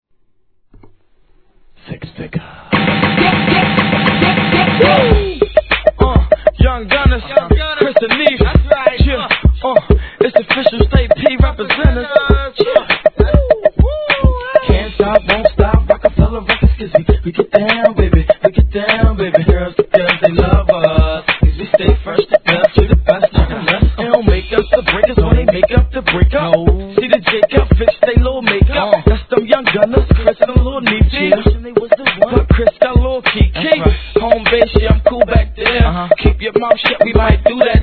HIP HOP/R&B
ポンポコポコポコポン♪でお馴染み'03年CLUB HIT!!